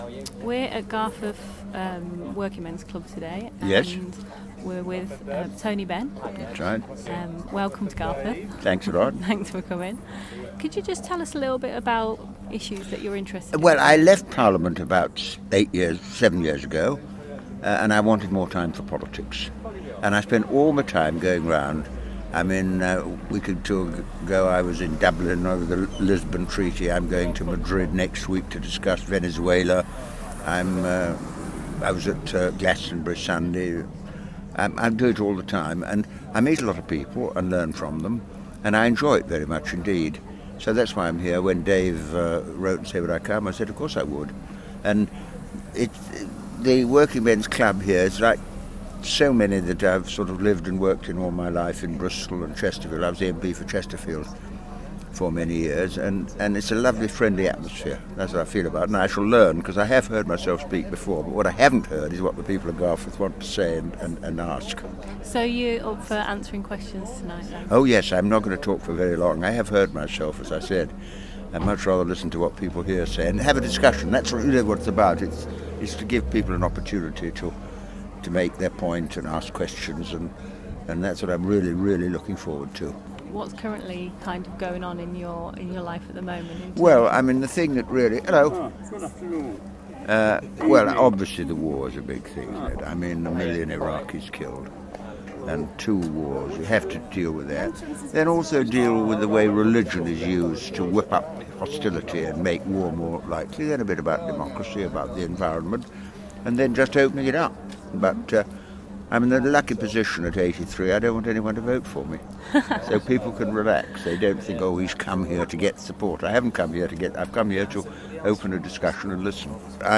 I interviewed Tony Benn, he was a lovely man. https